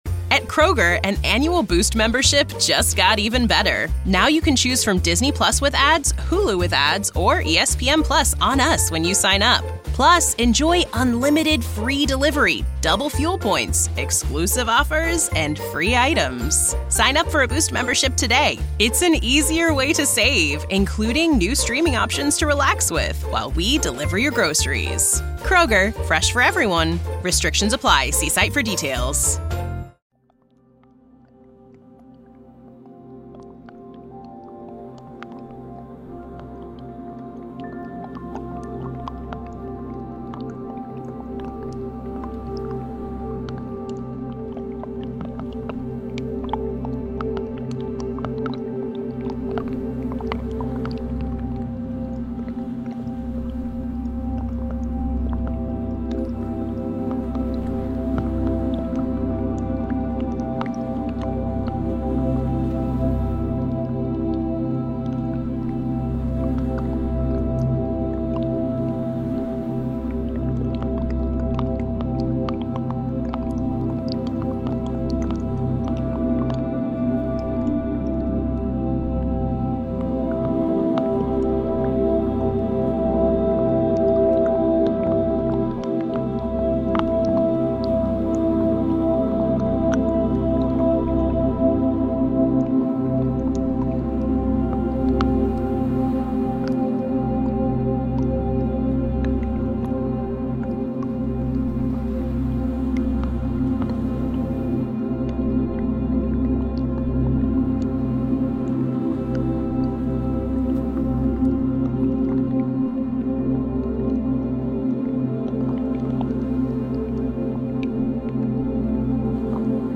Hypnosis and relaxation ｜Sound therapy
Here is a wonderful dreamland to help sleep and relax.